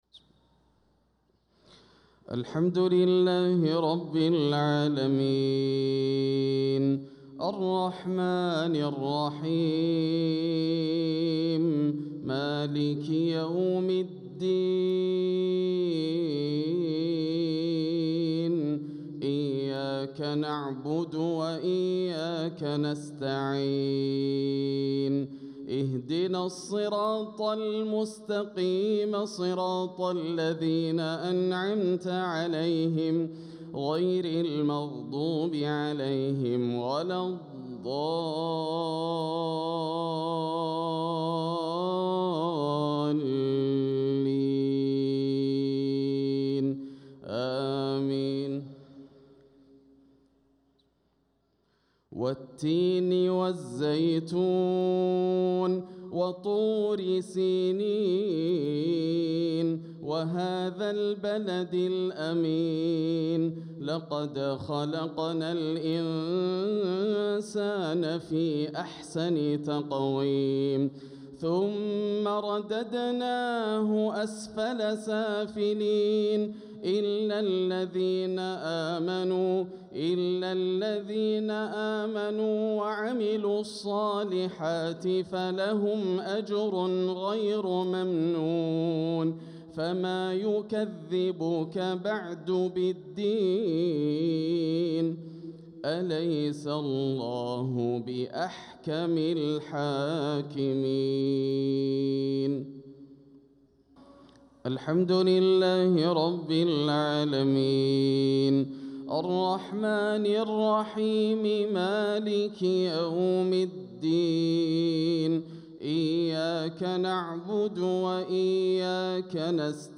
صلاة المغرب للقارئ ياسر الدوسري 19 ربيع الأول 1446 هـ
تِلَاوَات الْحَرَمَيْن .